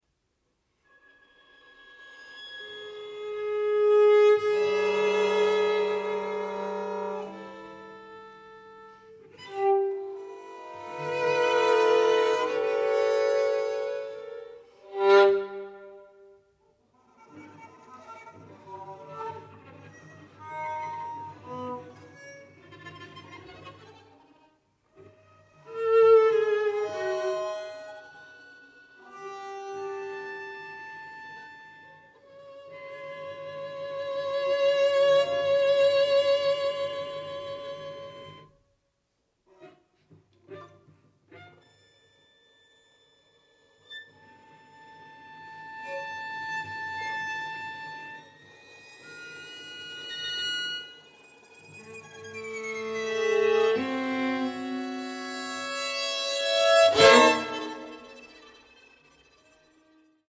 for string quartet